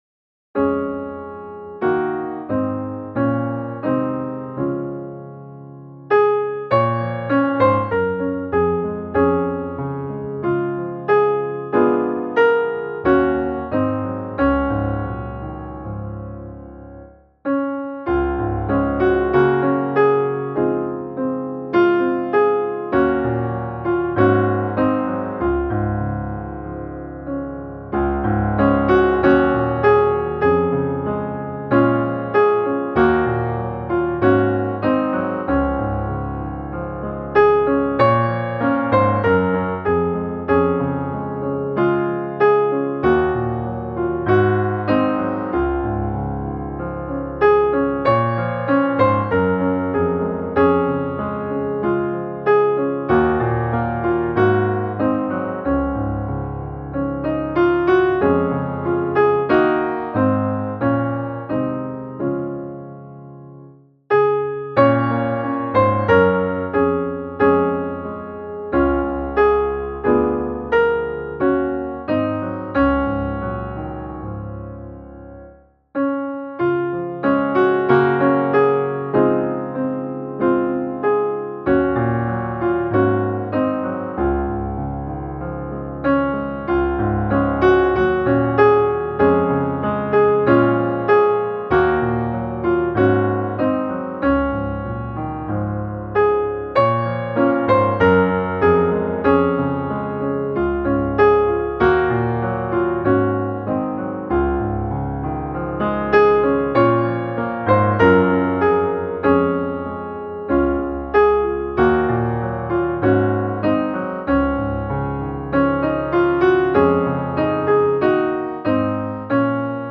Jag är en gäst och främling som mina fäder här - musikbakgrund